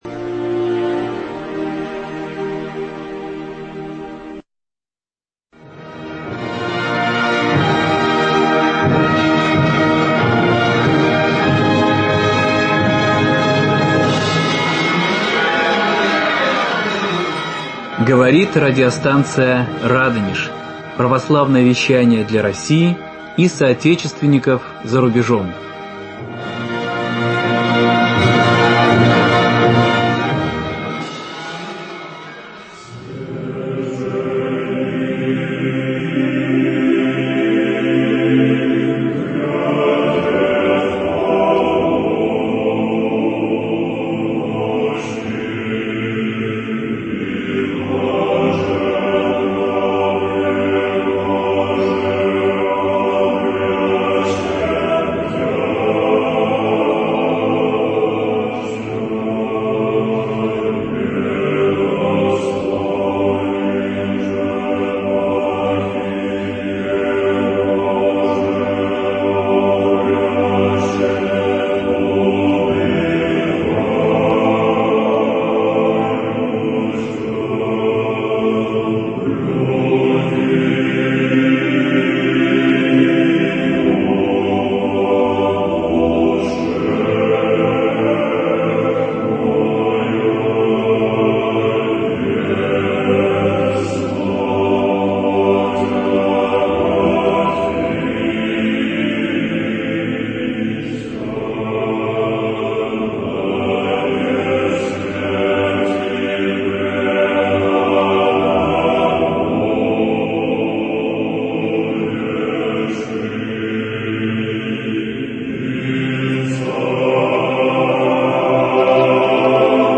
В исполнении народного артиста СССР Иннокентия Смоктуновского звучит книга Иова